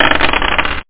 PSION CD 2 home *** CD-ROM | disk | FTP | other *** search / PSION CD 2 / PsionCDVol2.iso / Wavs / DICE ( .mp3 ) < prev next > Psion Voice | 1998-08-27 | 7KB | 1 channel | 8,000 sample rate | 1 second
DICE.mp3